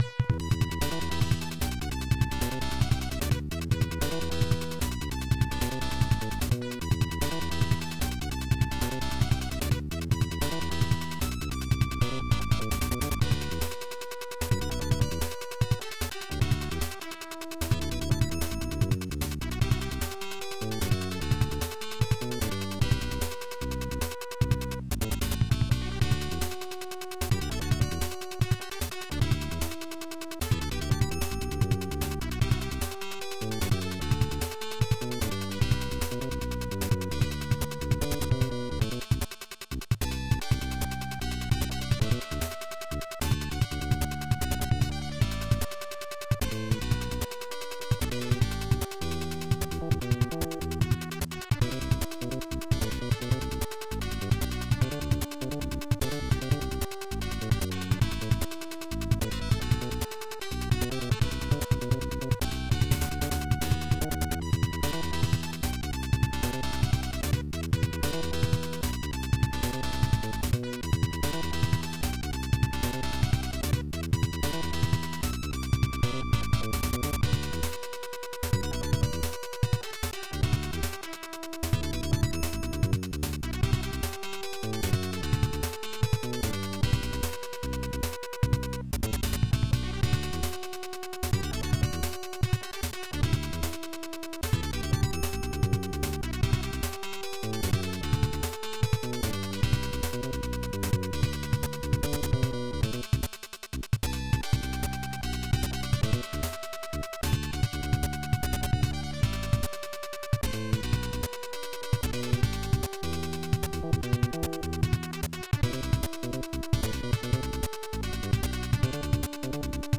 This file is an audio rip from a(n) Sega Master System game.